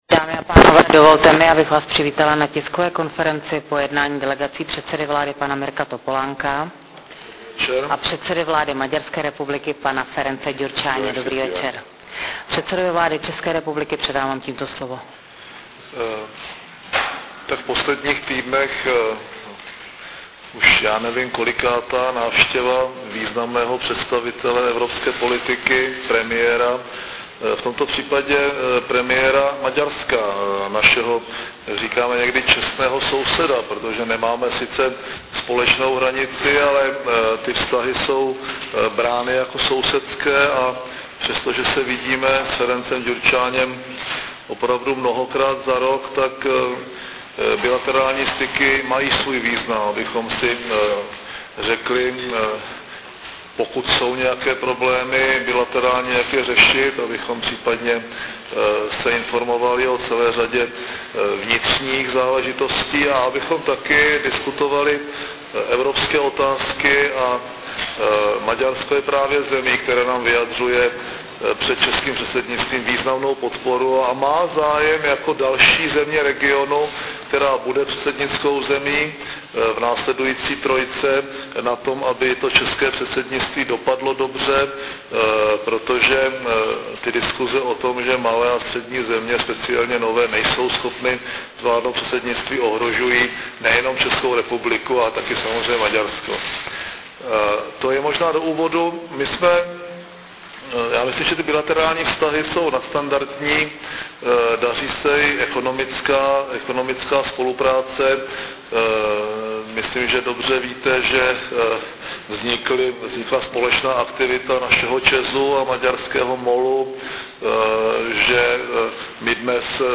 Předseda vlády Mirek Topolánek dnes ve Strakově akademii přivítal maďarského premiéra Ference Gyurscányho. Přinášíme fotogalerii a zvukový záznam tiskové konference po ukončení jednání obou premiérů.